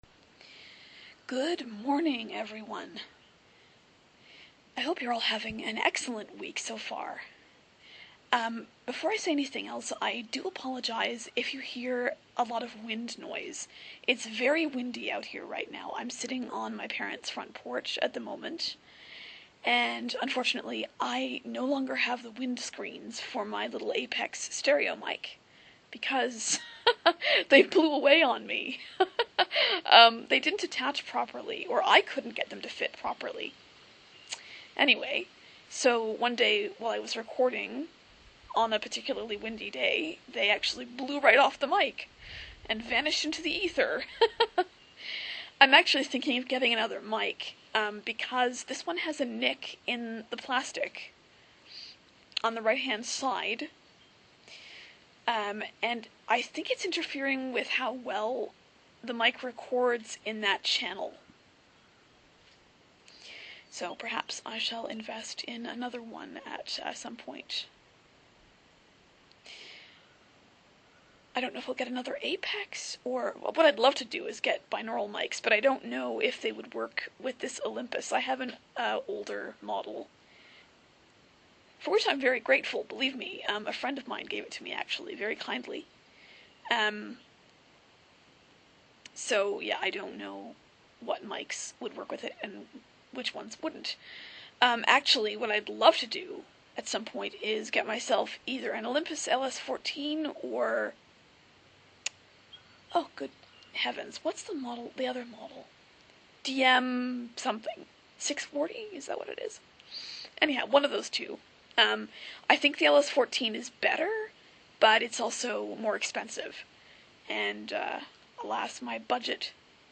Here's another Boo from the great outdoors at Oreole Acres.